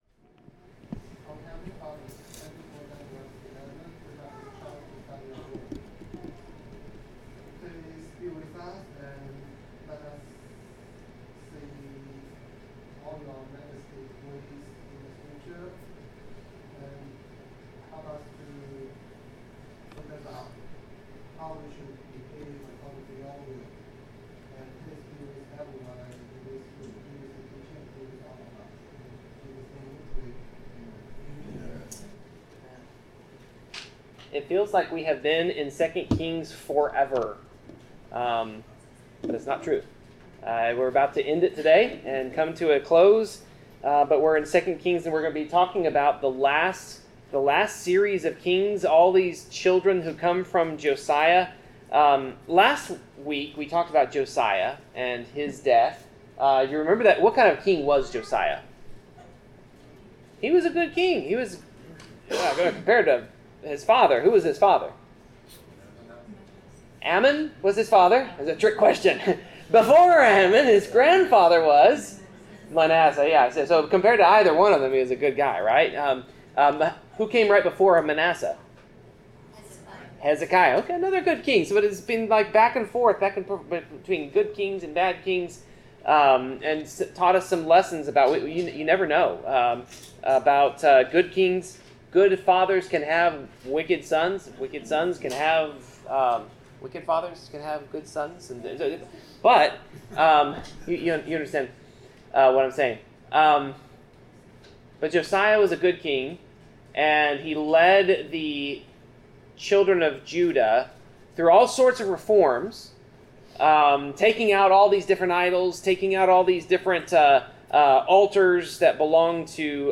Passage: 2 Kings 24-25 Service Type: Bible Class